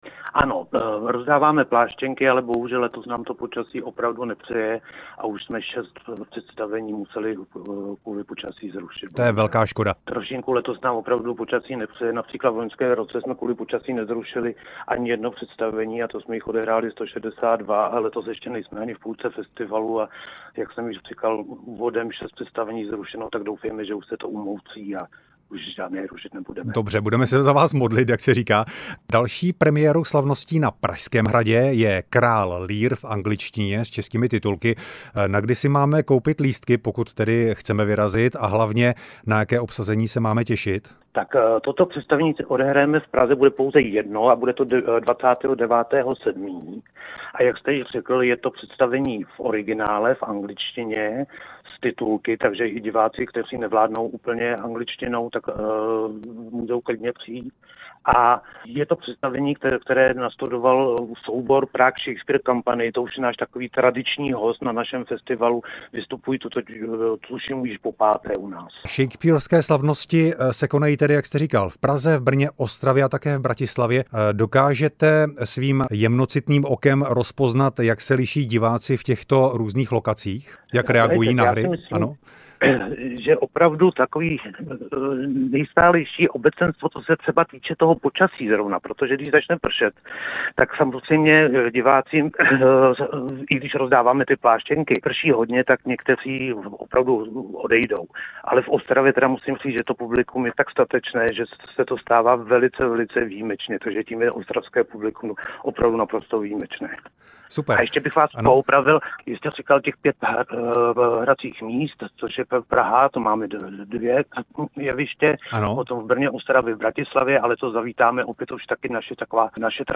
Moderátor